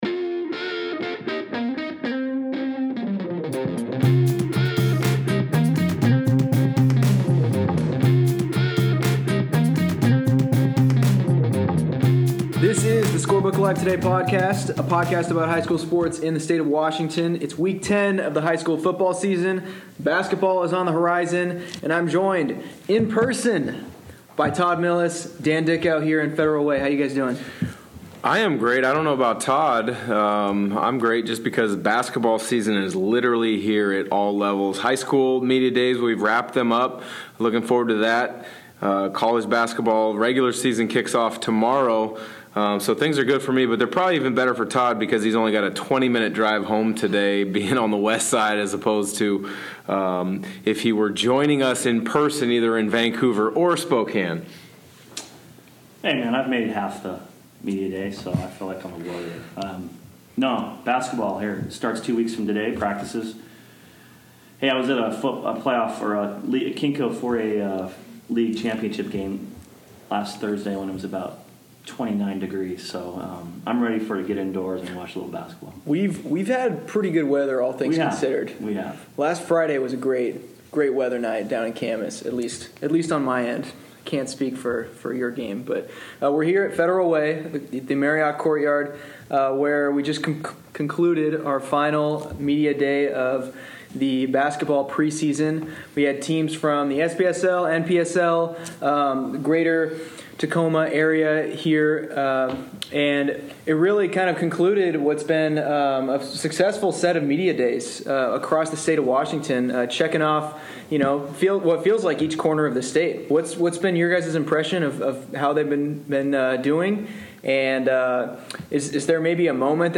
Analyst: Dan Dickau